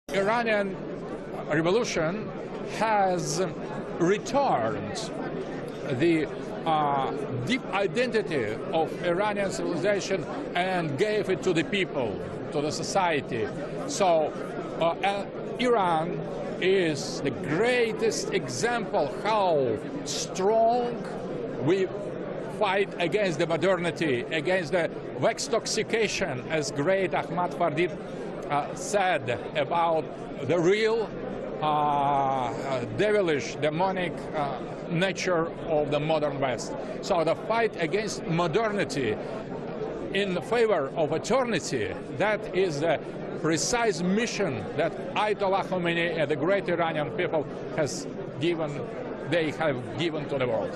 Interview with Alexander Dugin for Press TV